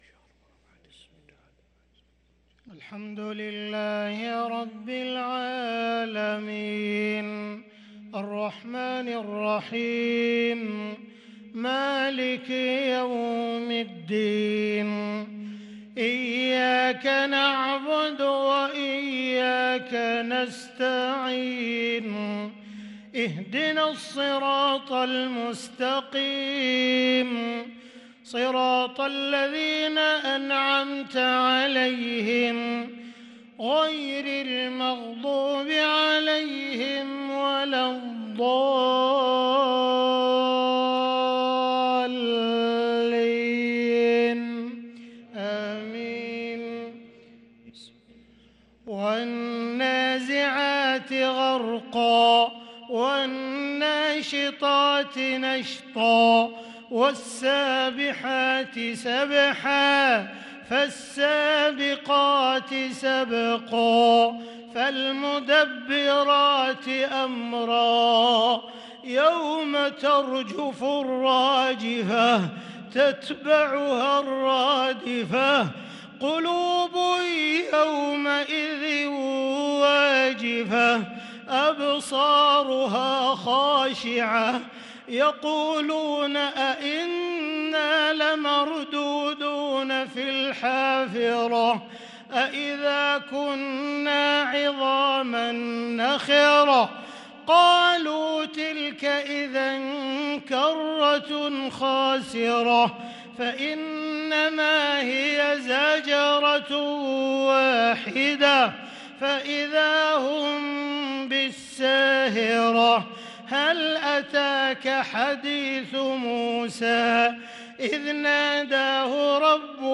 صلاة العشاء للقارئ عبدالرحمن السديس 15 جمادي الآخر 1444 هـ
تِلَاوَات الْحَرَمَيْن .